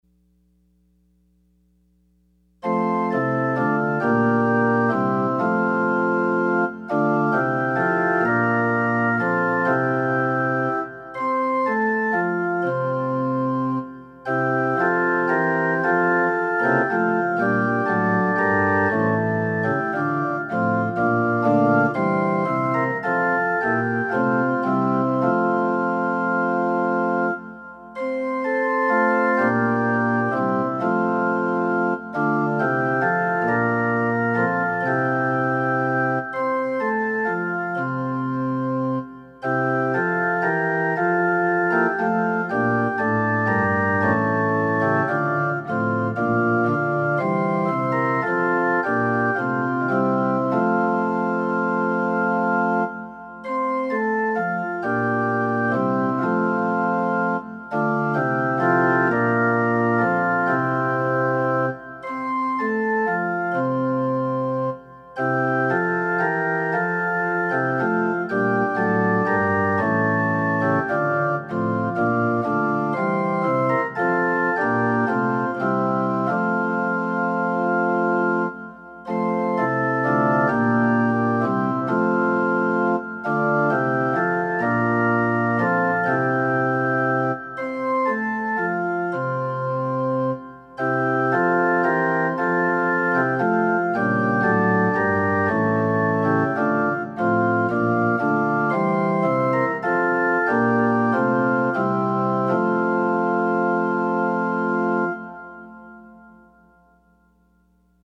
Opening Hymn – Come, thou almighty King #365